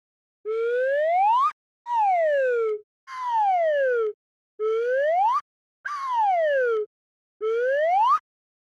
Cartoon_Whistle
ascend cartoon Cartoon Comedy descend Funny rise slide sound effect free sound royalty free Movies & TV